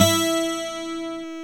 Index of /90_sSampleCDs/Roland - Rhythm Section/GTR_Steel String/GTR_12 String
GTR 12 STR05.wav